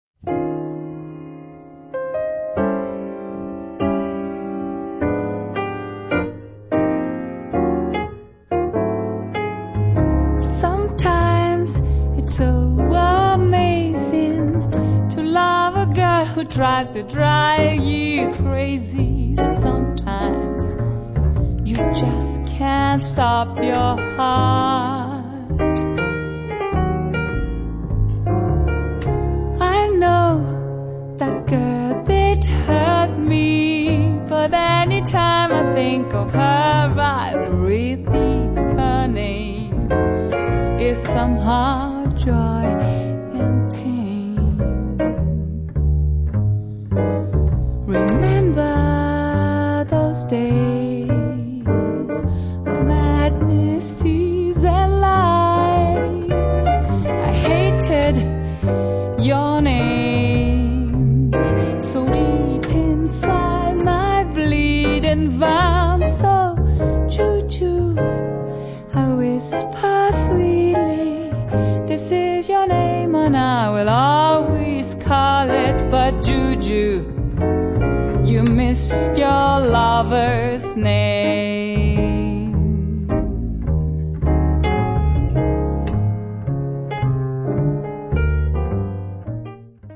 tromba, flicorno
Il trio fa un eccellente lavoro di supporto.
La bella e morbida voce